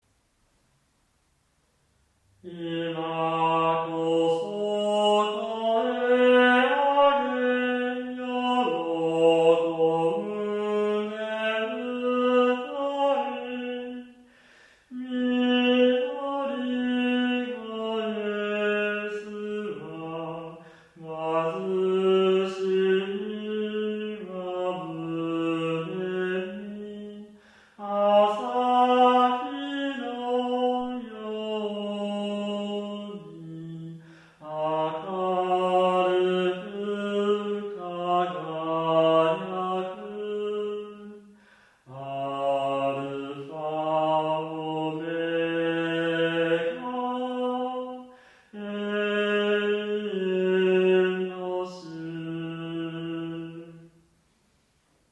ほぼピタゴラス音律で歌っています。下降したかと思えば上行音形に救われるメロディです。
３度下に落ち込んだ音を広く取って、上行でまた駆け込んでいく感じで歌います。
本当は子供の声のほうが抜けの良い倍音を出せるでしょうが、雰囲気だけで歌ってます。